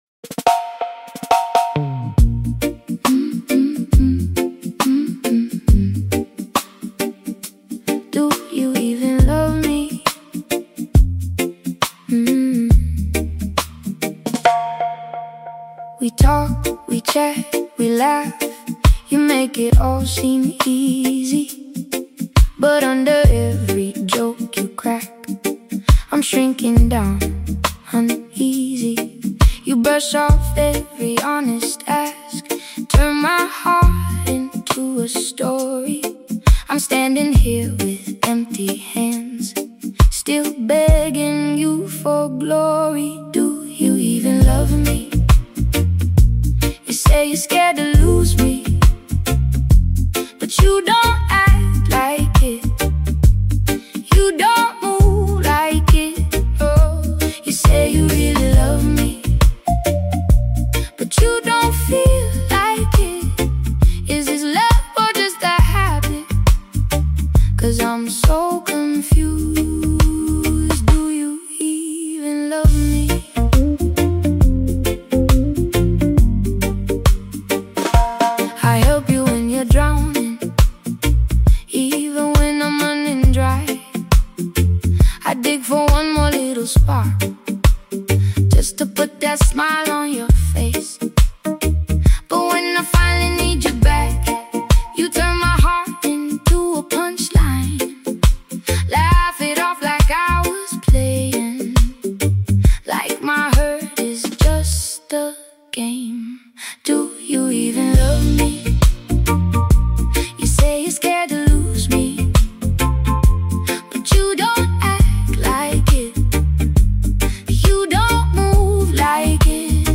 The sound does not try to distract you from that mood.